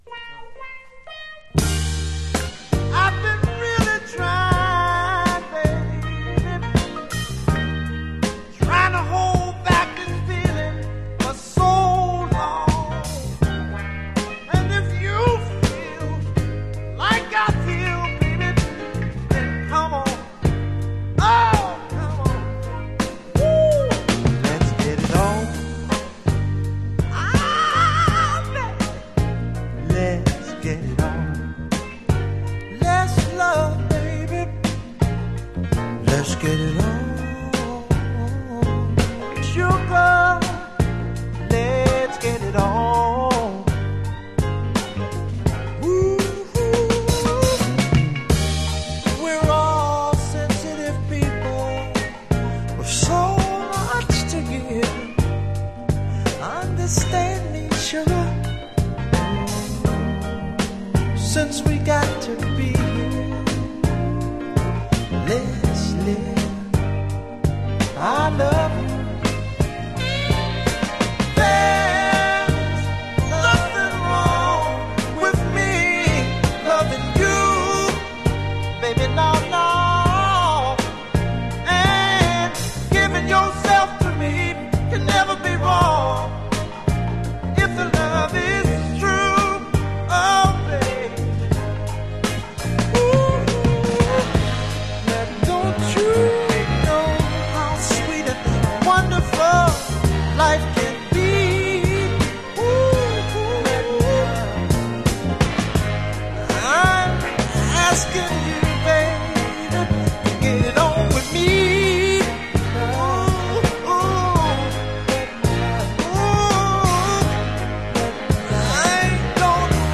Genre: Northern Soul, Motown Style